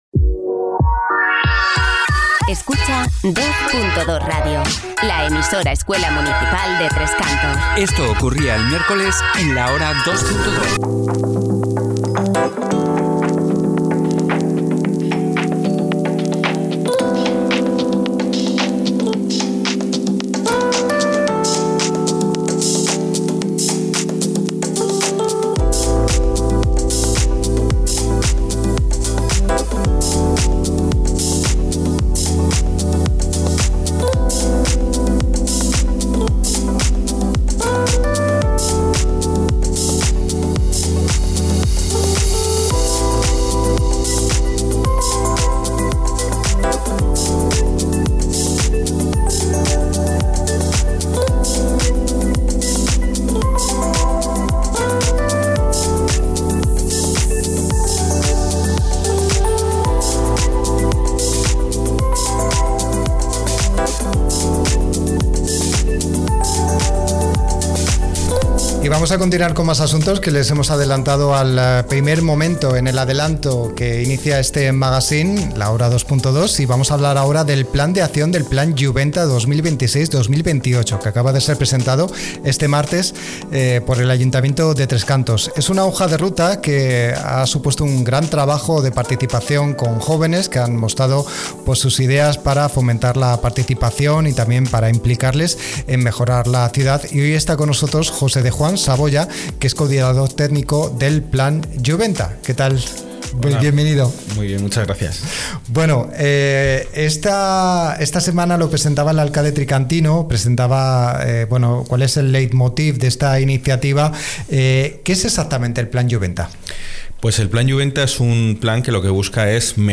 Entrevista-plan-iuventa.mp3